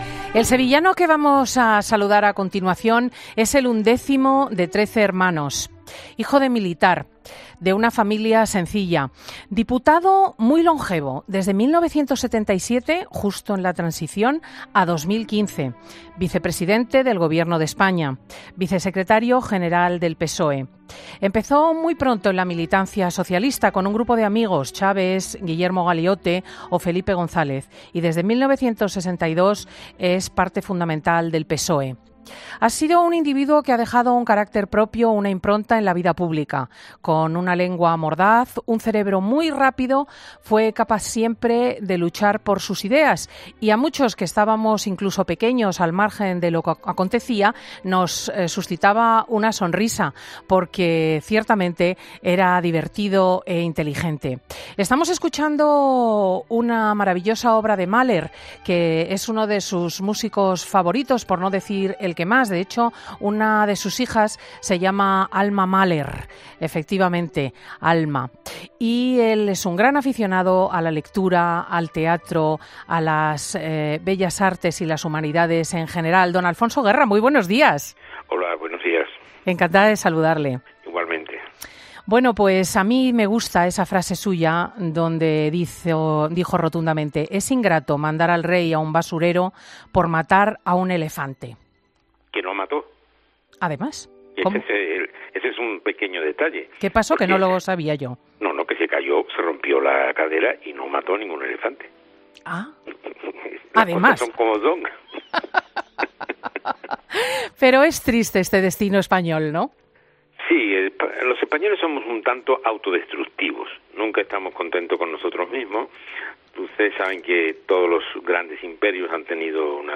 ESCUCHA LA ENTREVISTA COMPLETA | Alfonso Guerra en 'Fin de Semana'